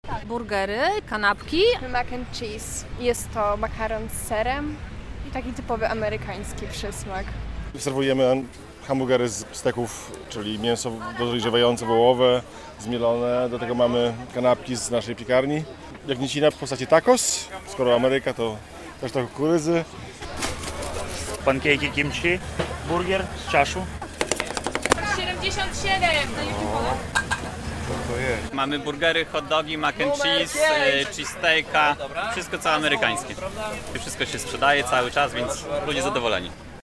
relacja z wydarzenia